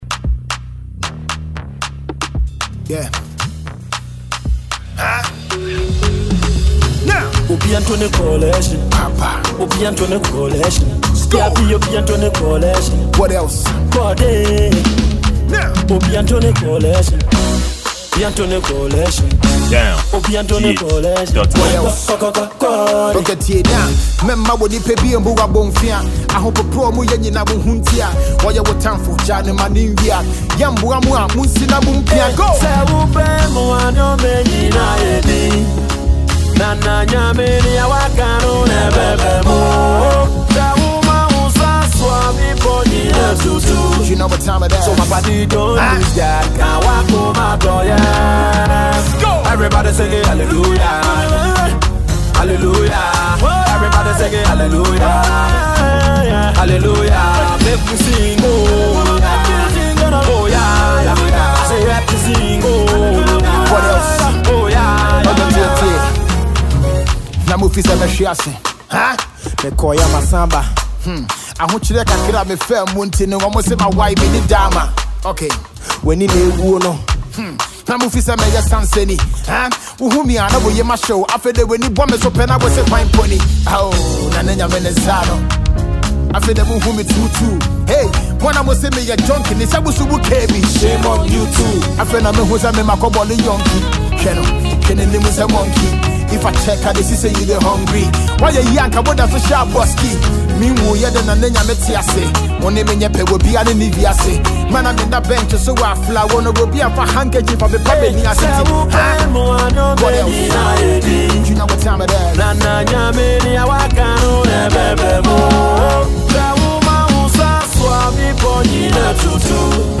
a King in Ghana’s rap music